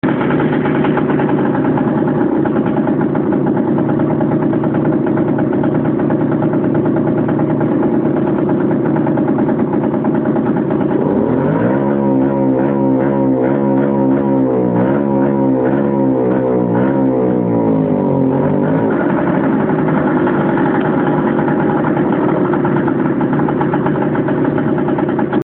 Datei „VespaPK50.mp3“ herunterladen
Vespa PK 50 (Bj.83) "stotter" und nimmt Gas schlecht an (Sounddatei)
Zur Datei: - 0 bis 10 Sek. Standgas - 10 bis 18 Sek. Vollgas (stottern) - danach wieder Standgas Danke schon mal und einen guten Start ins Wochenende Dateien VespaPK50.mp3 408.24 kB – 341 Downloads